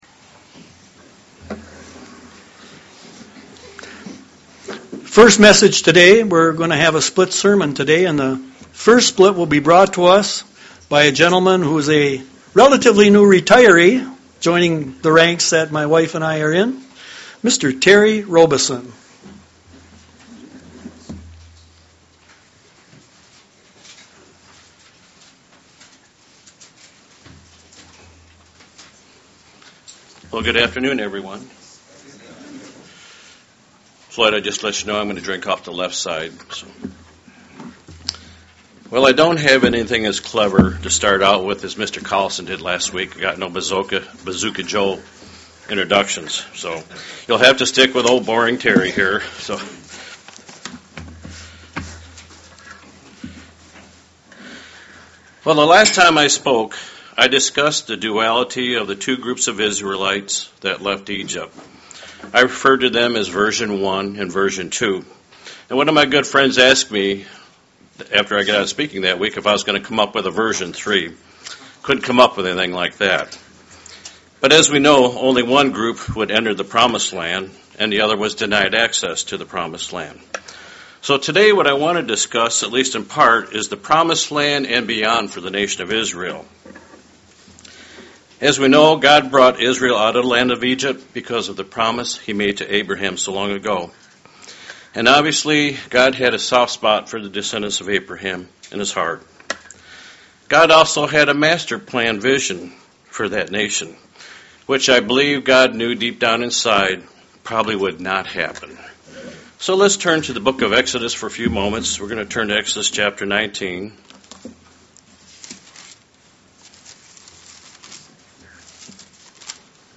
Given in Lansing, MI